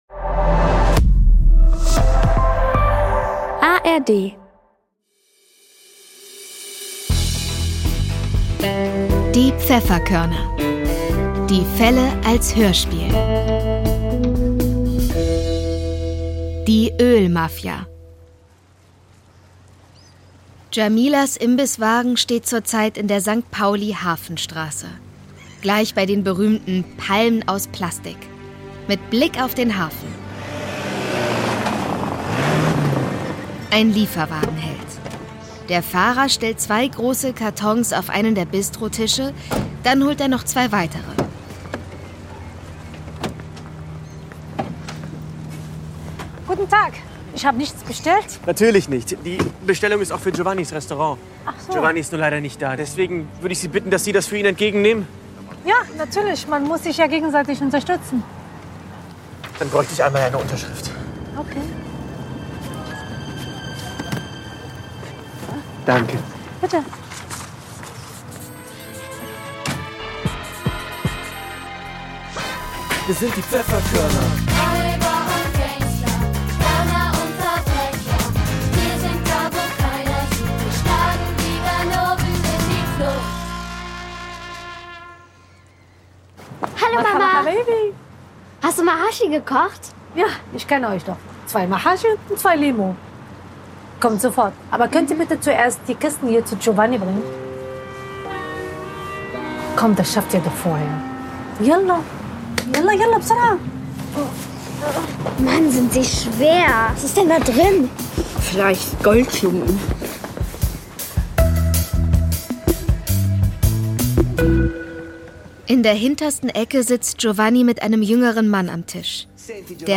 Folge 9 - Die Öl-Mafia ~ Die Pfefferkörner - Die Fälle als Hörspiel Podcast